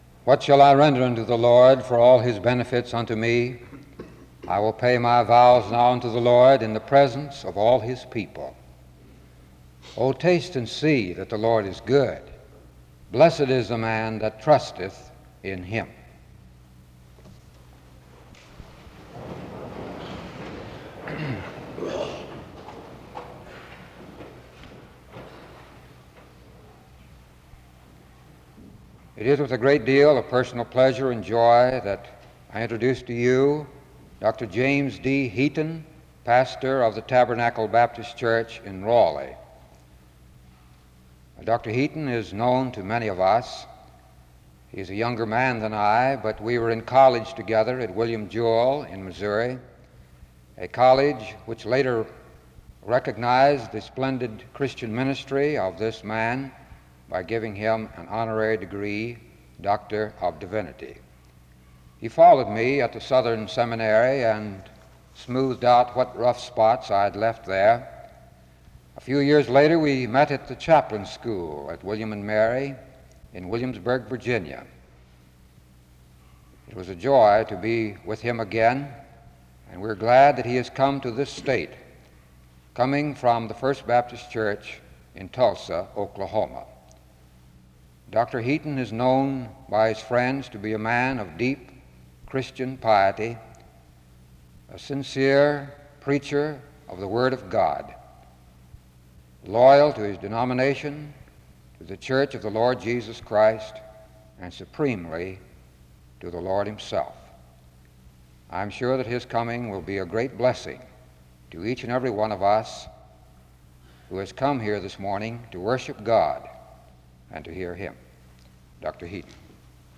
The service then concludes with a prayer and song (20:00-end).
SEBTS Chapel and Special Event Recordings SEBTS Chapel and Special Event Recordings